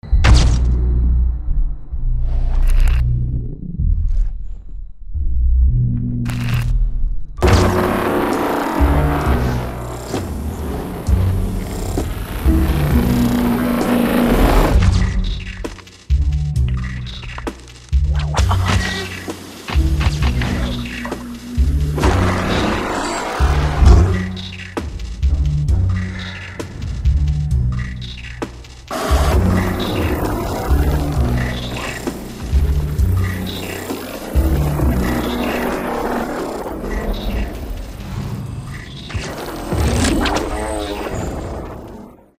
пугающие
из сериалов
страшные
жуткие